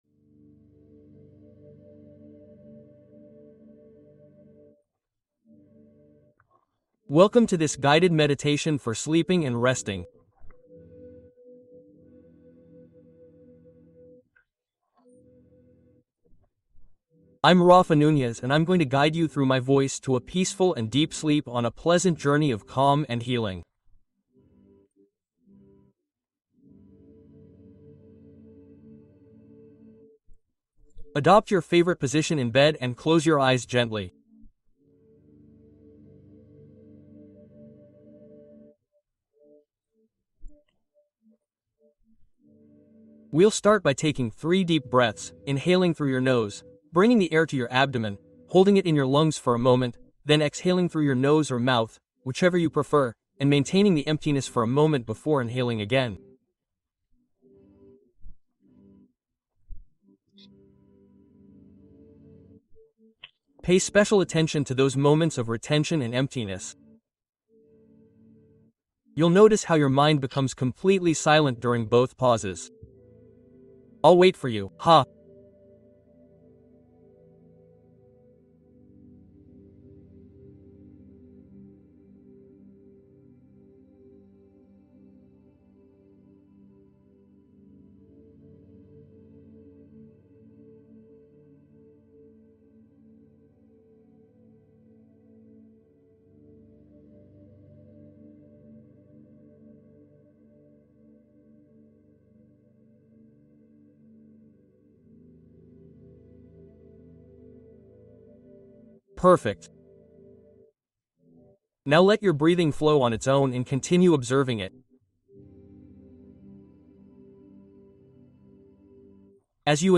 Meditación Sanadora con Cuento para Dormir